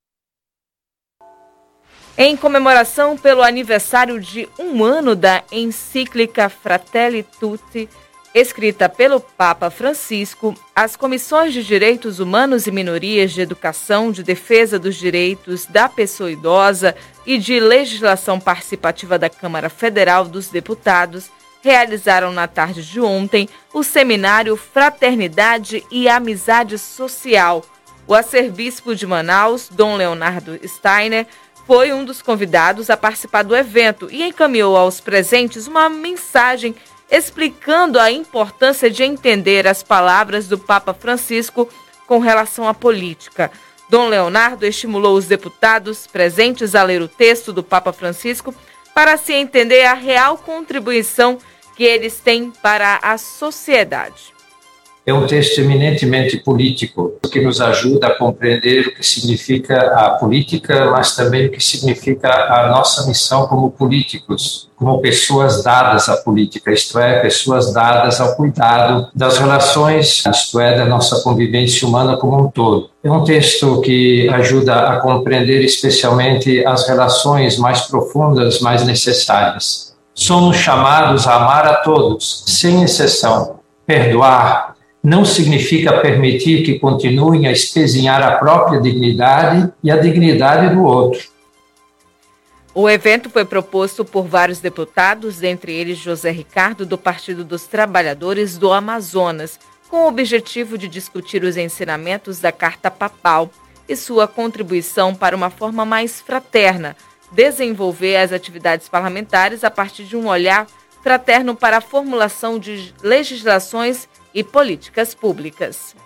O Arcebispo de Manaus, Dom Leonardo Steiner foi um dos convidados a participar do evento e encaminhou aos presentes uma mensagem explicando a importância em entender as palavras do Papa Francisco com relação à Política.